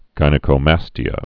(gīnĭ-kō-măstē-ə, jĭnĭ)